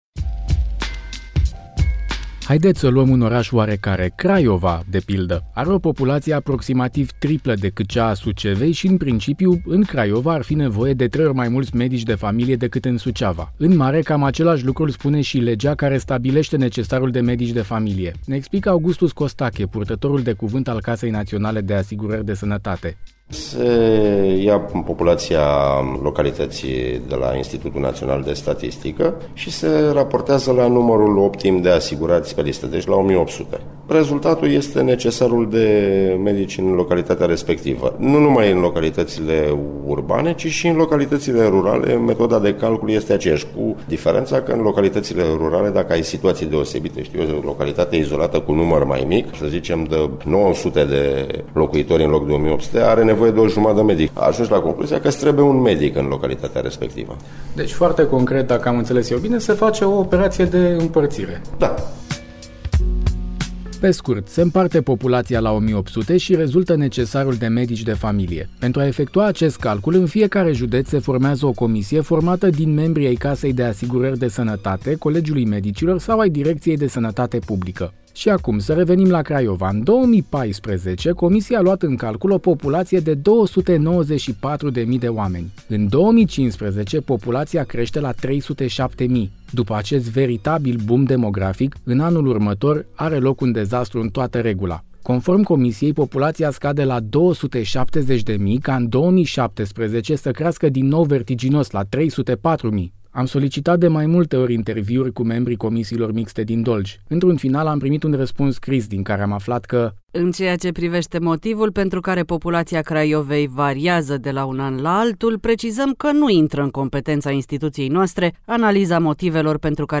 De-a alba-neagra cu medicii de familie (REPORTAJ) : Europa FM